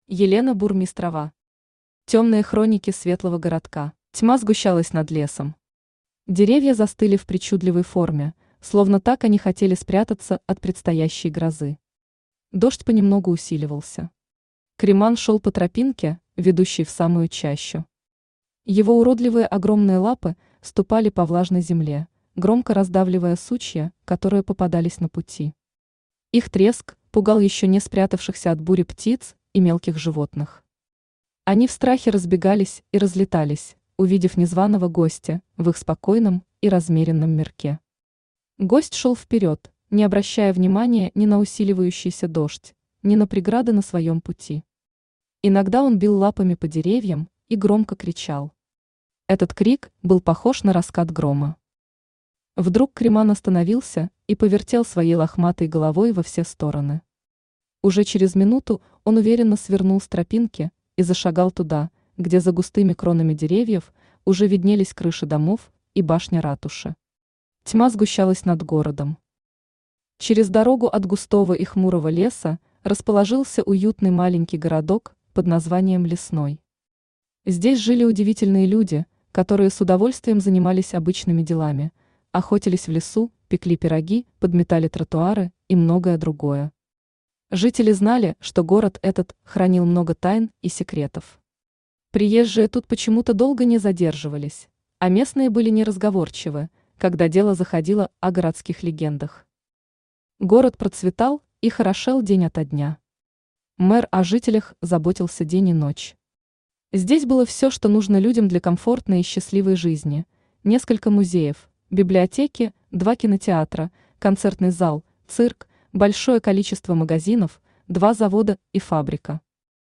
Аудиокнига Темные хроники светлого городка | Библиотека аудиокниг
Aудиокнига Темные хроники светлого городка Автор Елена Валерьевна Бурмистрова Читает аудиокнигу Авточтец ЛитРес.